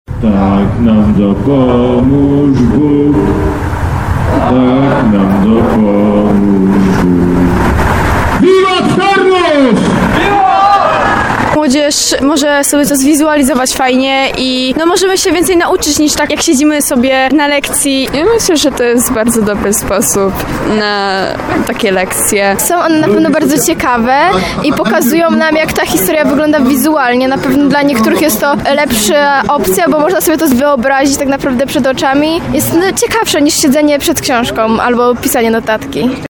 31wiwat-tarnow.mp3